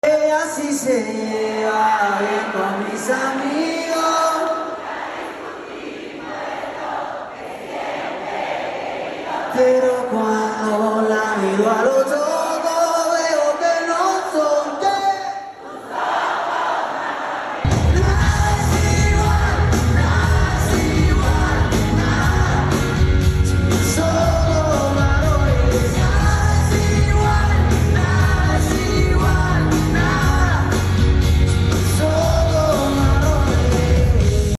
tanto le gusta que sus fans canten este temazo
algo que pudimos experimentar en el Teatro Metropolitan